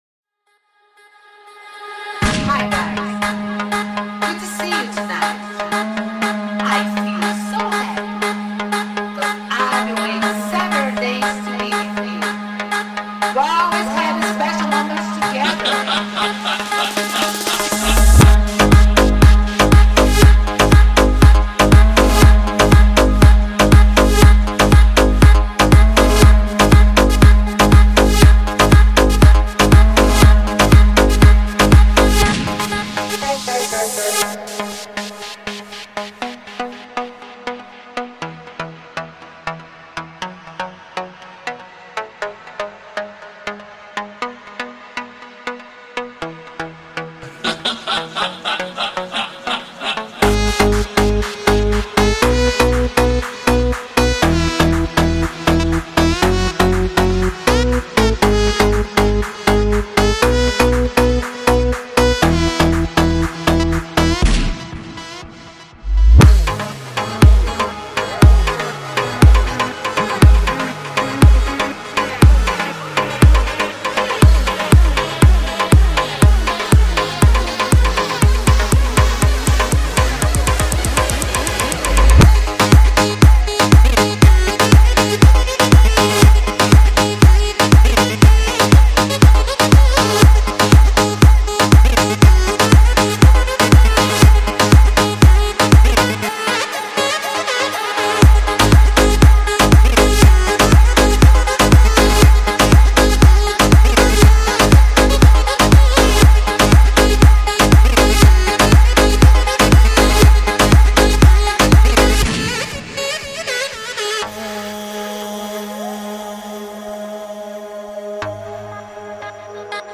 это энергичная трек в жанре EDM